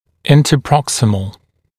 [ˌɪntəˈprɔksɪməl][ˌинтэ’проксимэл]интерпроксимальный, межпроксимальный, межзубный